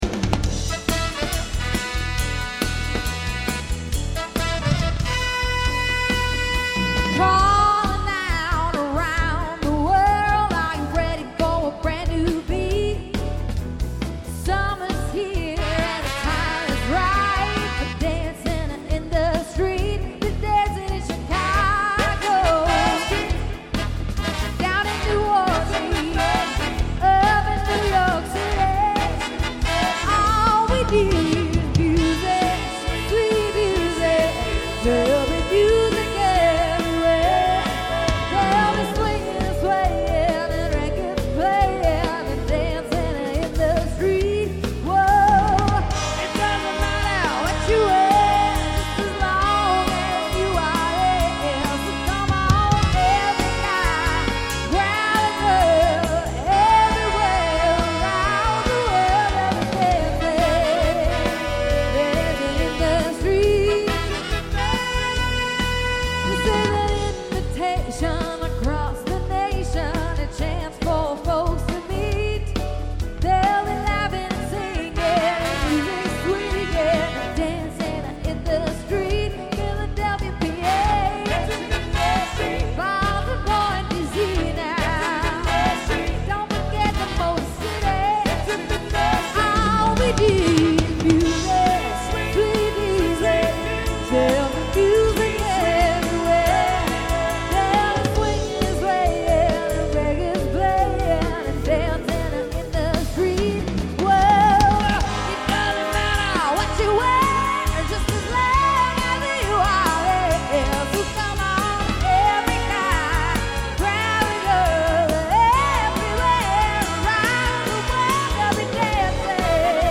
3 horns: trumpet, tenor sax, trombone, master rhythm.
All dance charts.
rock, vocal